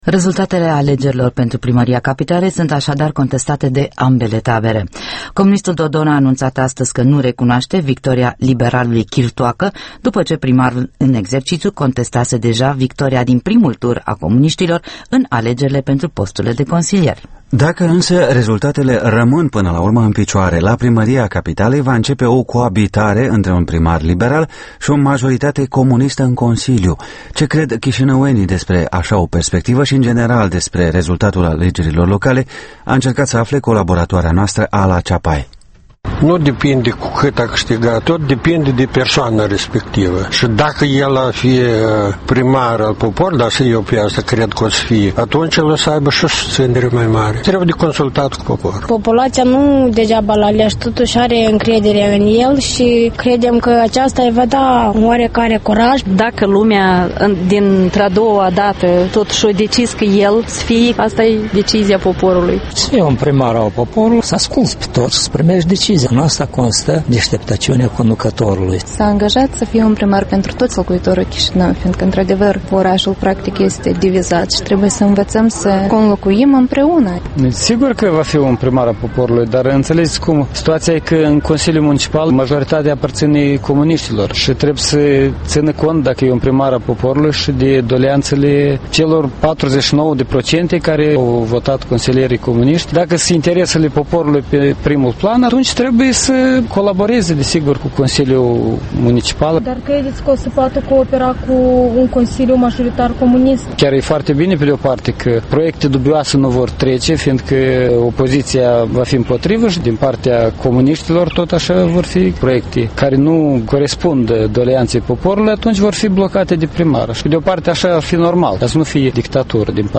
Vox pop la Chișinău după alegeri